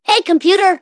synthetic-wakewords
ovos-tts-plugin-deepponies_Bart Simpson_en.wav